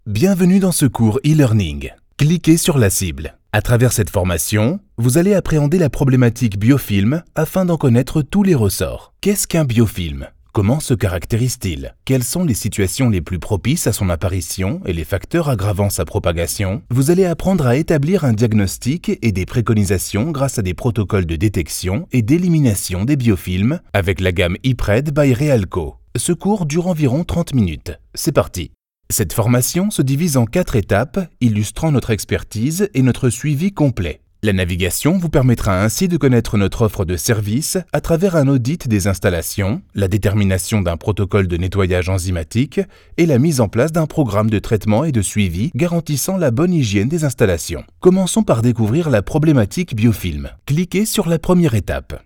Comercial, Natural, Versátil, Amable, Empresarial
E-learning
A true "chameleon" voice, he adapts his tone and delivery to convey the perfect message.